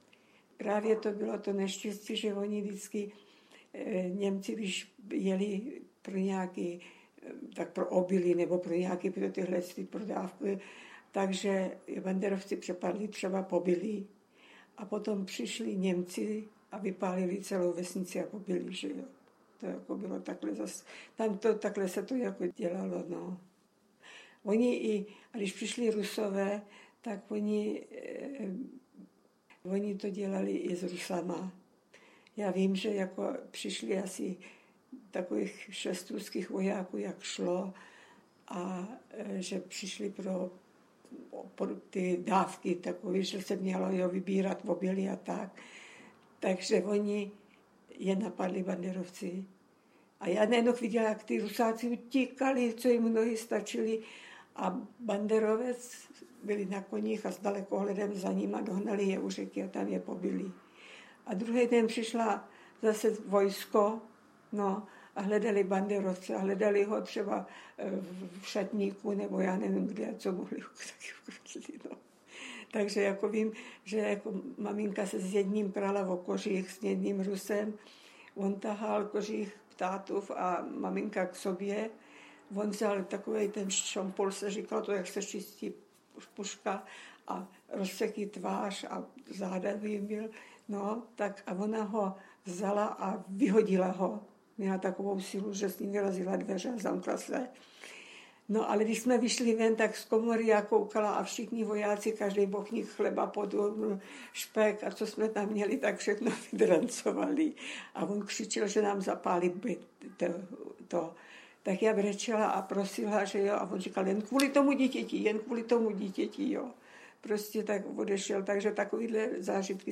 Rozhovory-Post Bellum © - Paměť národa